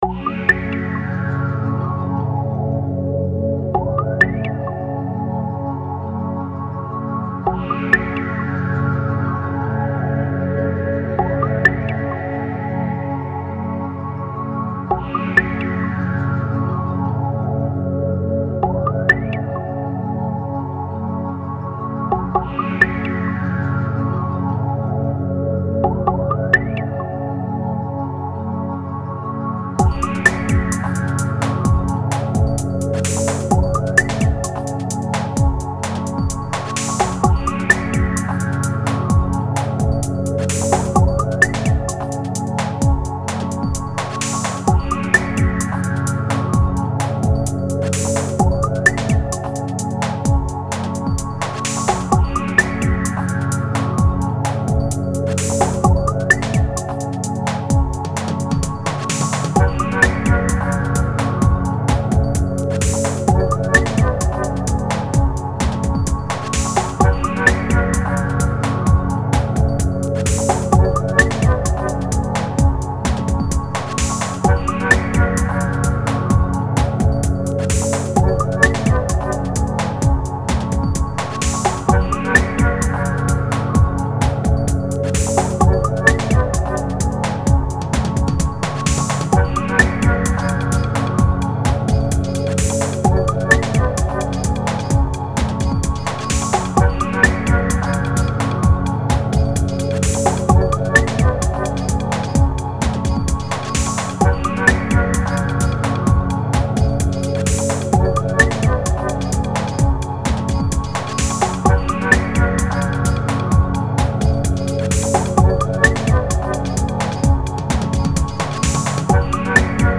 .mp3] (Downtempo)